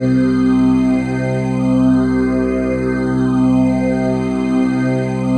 PHASEPAD27-LR.wav